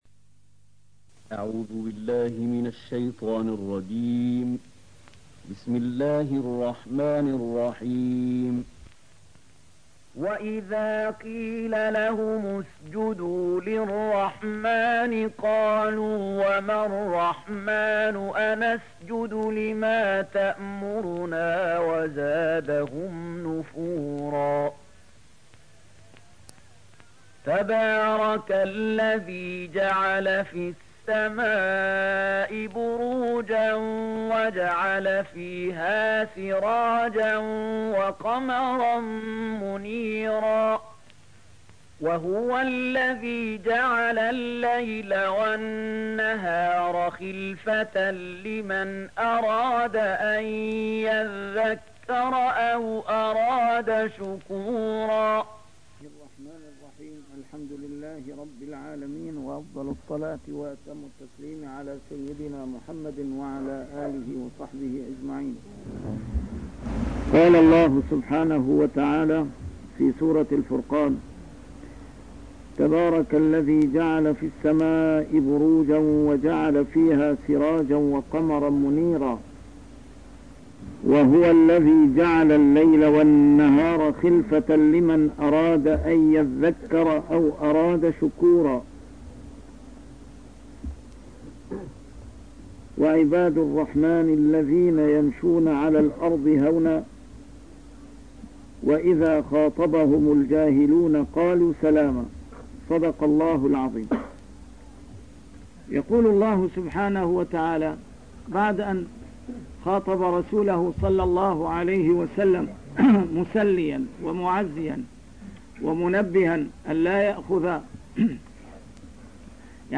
A MARTYR SCHOLAR: IMAM MUHAMMAD SAEED RAMADAN AL-BOUTI - الدروس العلمية - تفسير القرآن الكريم - تسجيل قديم - الدرس 217: الفرقان 61-62